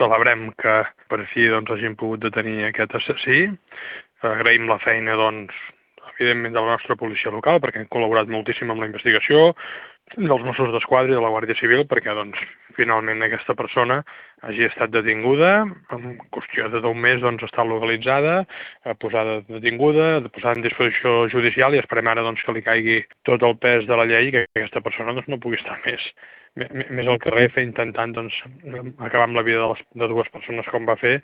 En declaracions a Ràdio Calella TV, l’alcalde Marc Buch ha celebrat la detenció del presumpte autor dels fets, investigat per una doble temptativa d’homicidi per l’agressió a qui era la seva parella i a la mare d’aquesta.